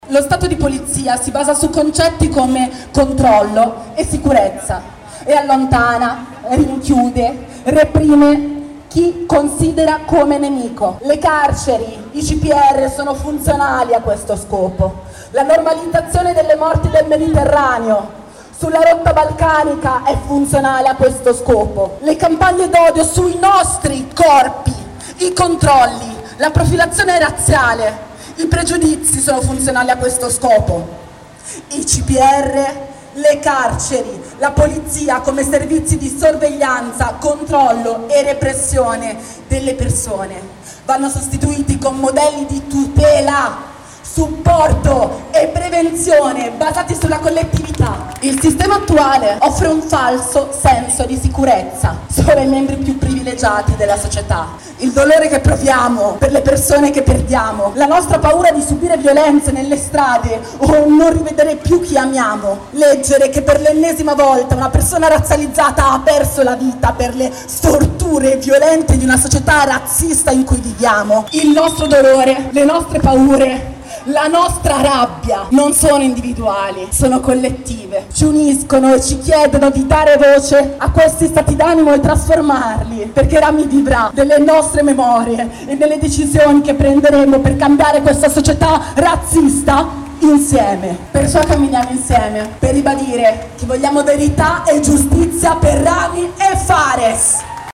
Nel corteo pacifico e nonviolento, che da piazza San Babila si è mosso fino alla Stazione Centrale, regnava la rabbia contro il razzismo sistematico e il falso senso di allarme sociale che tende a bersagliare gli ultimi, i più indifesi. Queste le parole pronunciate durante il corteo da un’attivista del “Coordinamento antirazzista”.